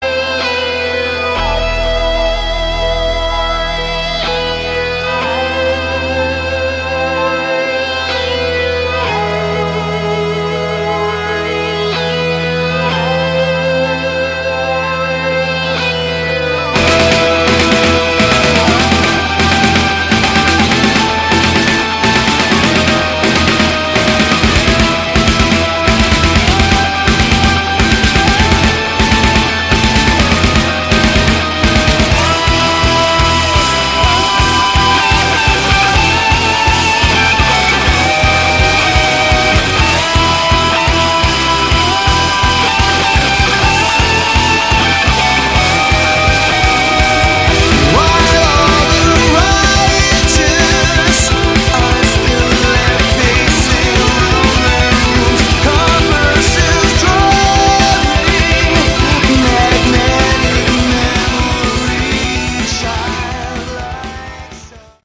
Category: Hard Rock
guitars
vocals
drums
keyboards